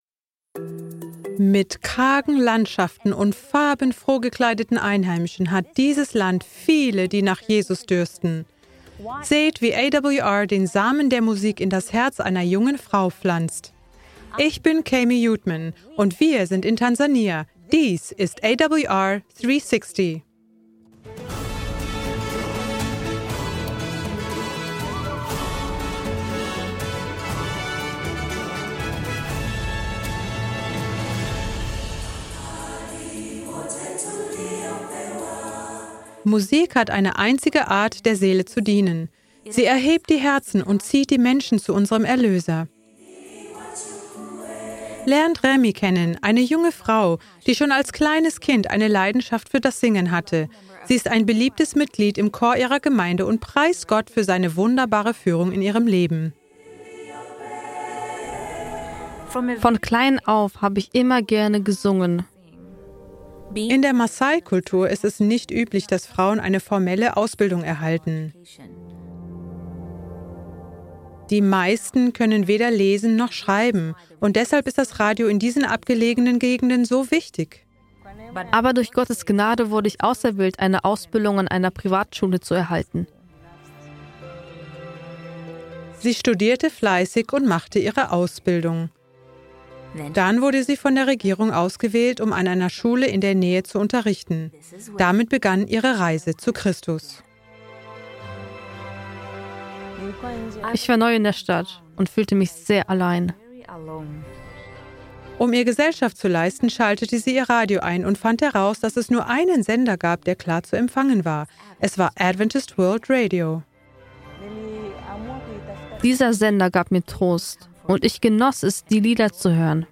Kategorie Zeugnis